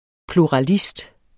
Udtale [ pluɑˈlisd ]